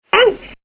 Kid saying Ouch.mp3